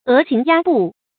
鵝行鴨步 注音： ㄜˊ ㄒㄧㄥˊ ㄧㄚ ㄅㄨˋ 讀音讀法： 意思解釋： 步：走。象鵝和鴨子那樣的走路。比喻步行緩慢。